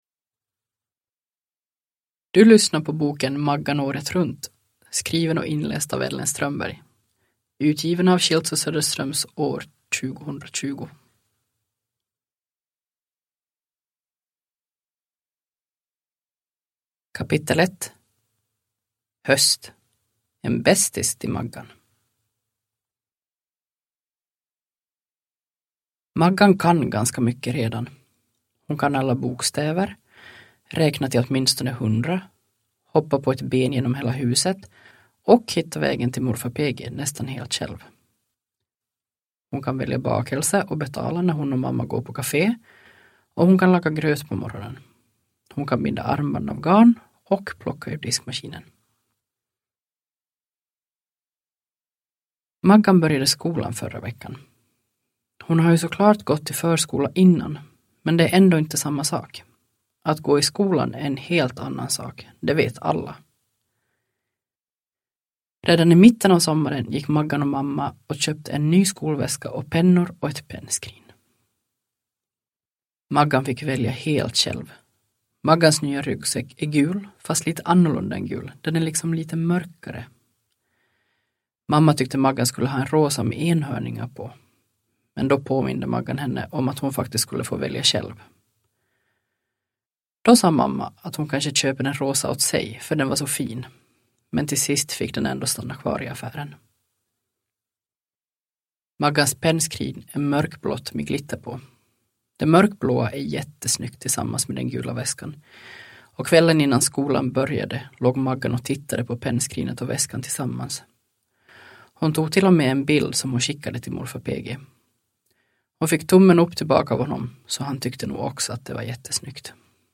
Maggan året runt – Ljudbok